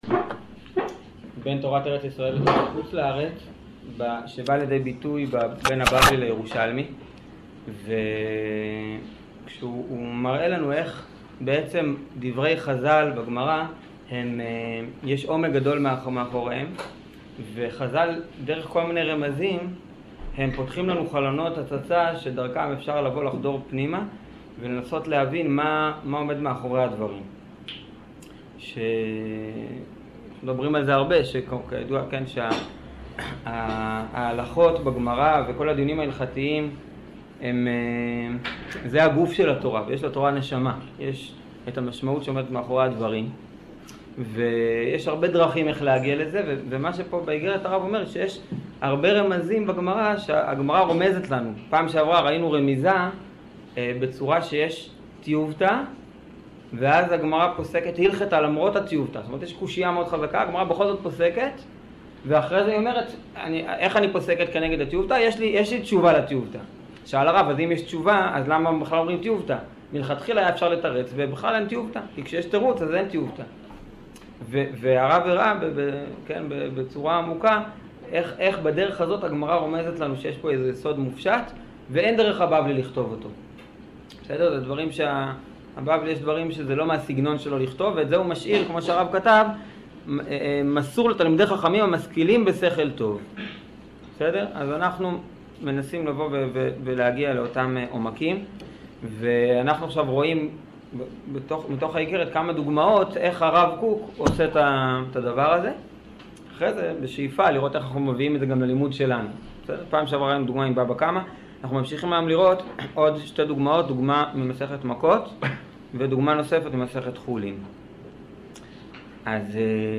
שיעור אגרת קג'